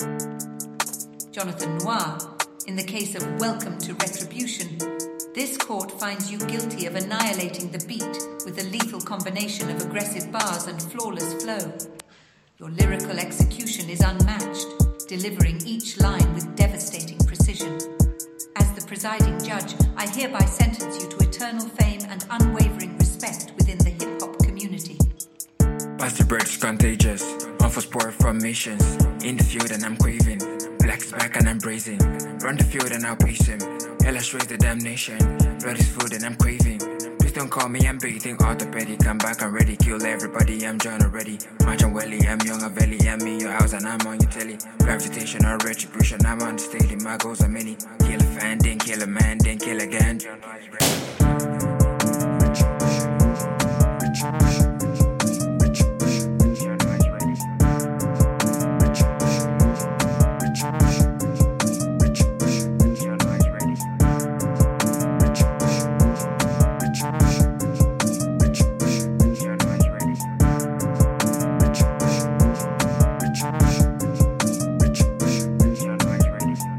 sharp, calculated, and relentless